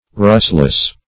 Meaning of rustless. rustless synonyms, pronunciation, spelling and more from Free Dictionary.
Search Result for " rustless" : Wordnet 3.0 ADJECTIVE (1) 1. without rust ; The Collaborative International Dictionary of English v.0.48: Rustless \Rust"less\, a. Free from rust.